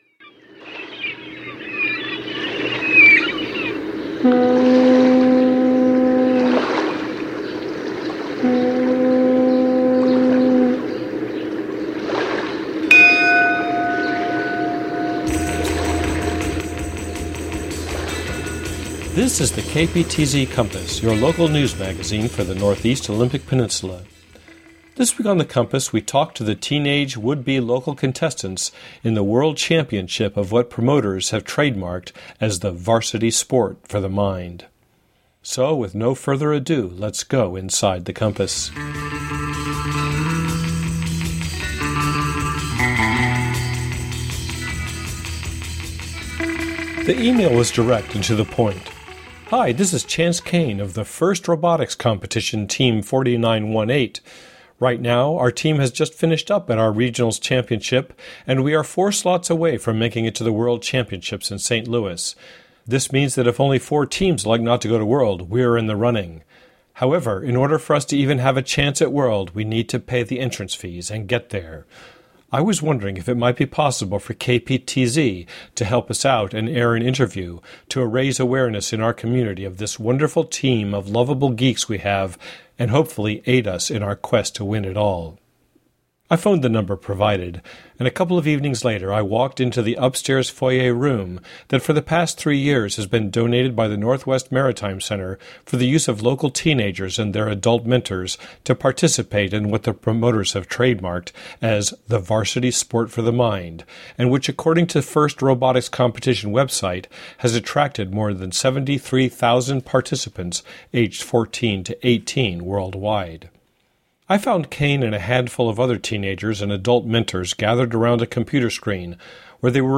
Genre: Radio News.